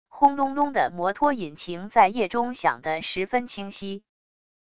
轰隆隆的摩托引擎在夜中响得十分清晰wav音频